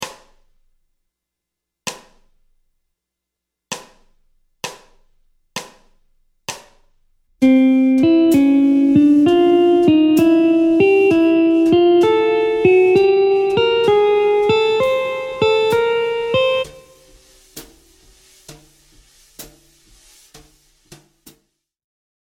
Mode Mixolydien ( V7 gamme Majeure)
{1 2 3 4 5 6 b7}
Montée de gamme
Gamme-bop-asc-Pos-31-C-Mixo-1.mp3